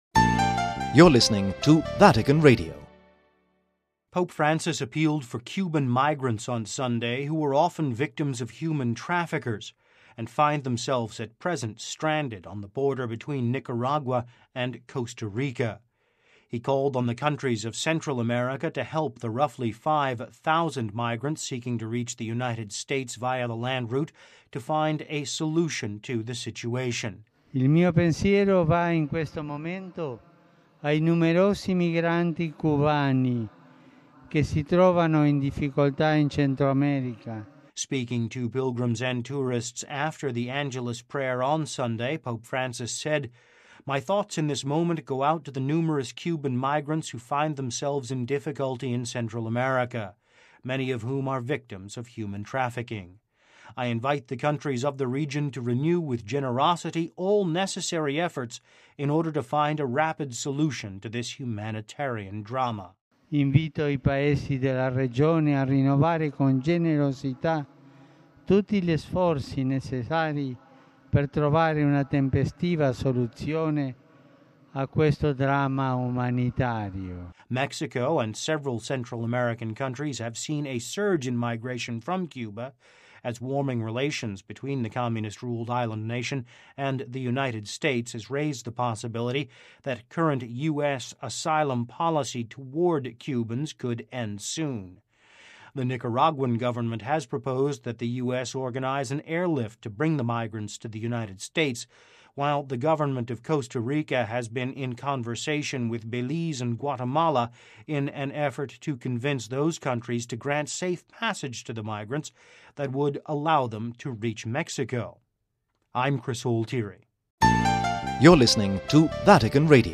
Speaking to pilgrims and tourists after the Angelus prayer on Sunday, Pope Francis said, “My thoughts in this moment go out to the numerous Cuban migrants who find themselves in difficulty in Central America, many of whom are victims of human trafficking: I invite the countries of the region to renew with generosity all necessary efforts in order to find a rapid solution to this humanitarian drama.”